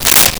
Paper Quick Tear
Paper Quick Tear.wav